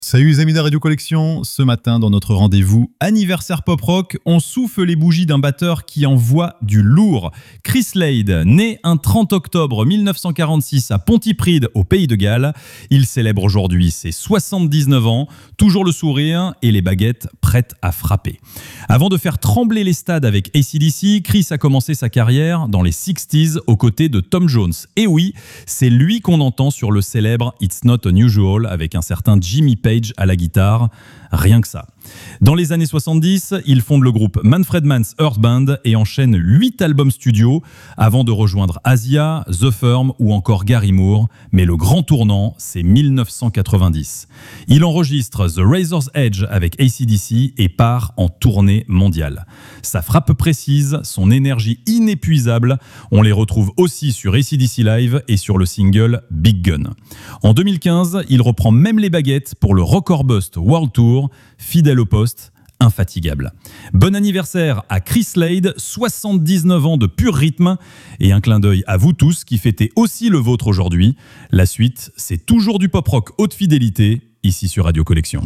Une chronique vivante qui mêle souvenirs, anecdotes et découvertes pour un véritable voyage quotidien dans l’histoire des artistes préférés des fans de Pop Rock, des années 70 à aujourd’hui.